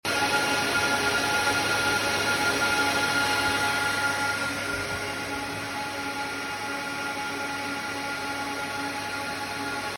Dos Cisco ISE y un Catalyst 9300L suenan como un avión despegando